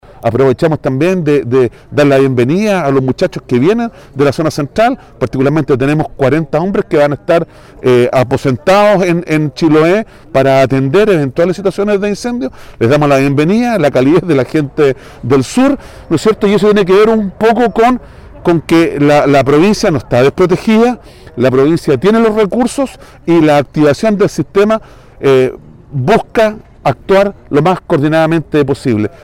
El Director de ONEMI, Alejandro Vergés, aseguró que el sistema de protección civil está funcionando, aprovechando la oportunidad para dar la bienvenida a los brigadistas que llegaron a la isla para apoyar las labores.